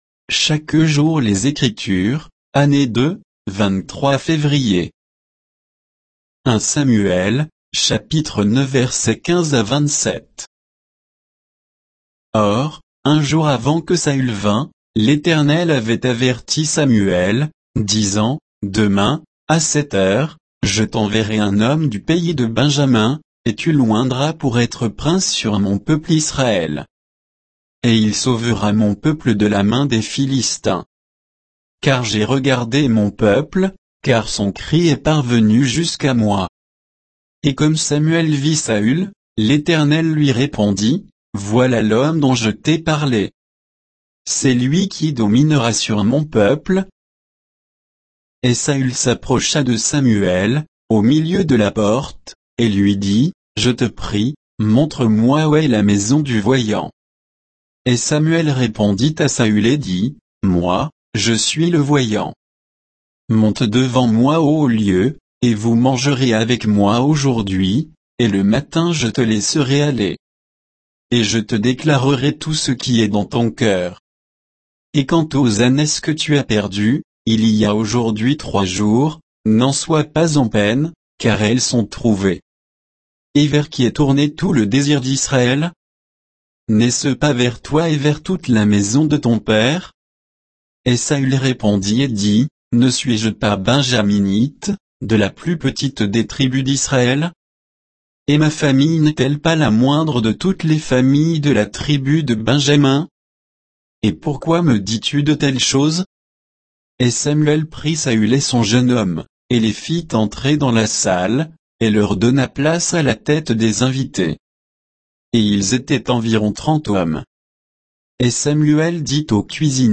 Méditation quoditienne de Chaque jour les Écritures sur 1 Samuel 9